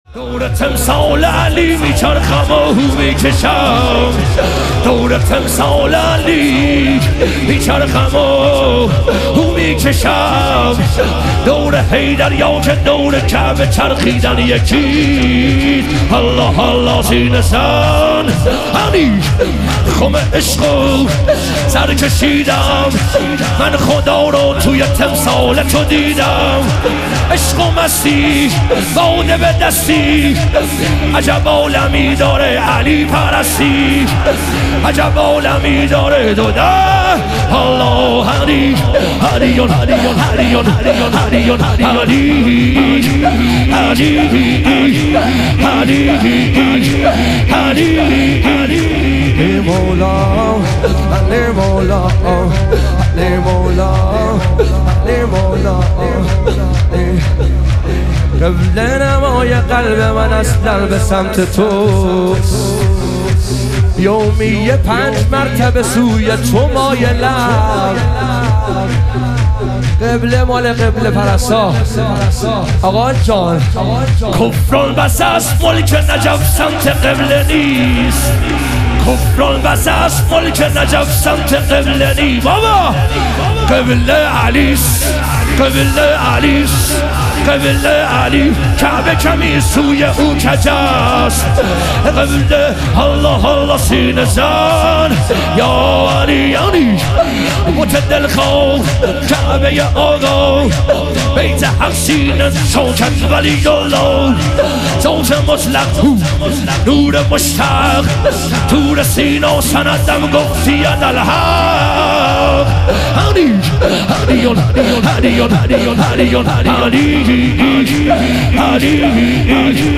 لیالی قدر و شهادت امیرالمومنین علیه السلام - شور